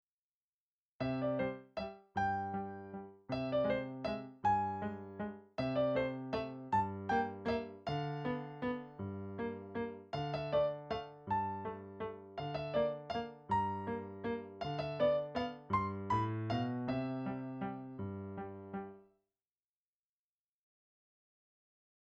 Voicing: 1 Piano 4 Hands